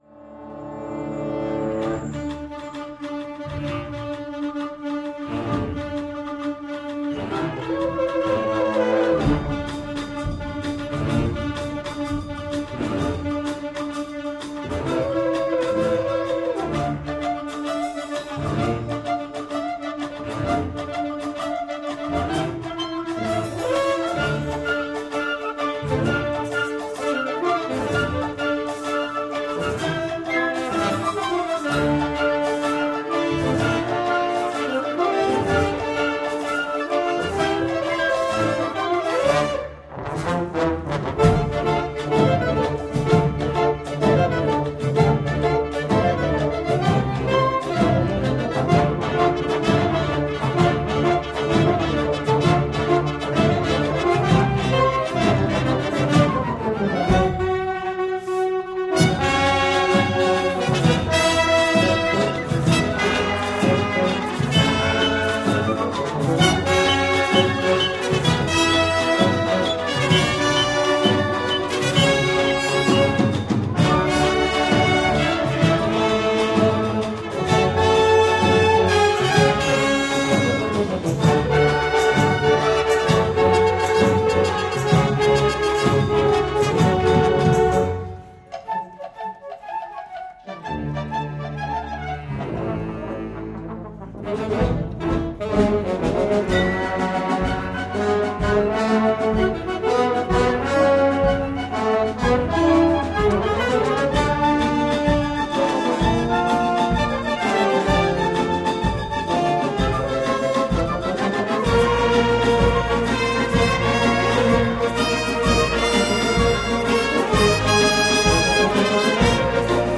Ecoute du Concert de Noël 2010 en live de l'Orchestre de Gaillard
Concert de Noël 2010 en live depuis l'Espace Louis Simon de Gaillard par l'Orchestre de Gaillard.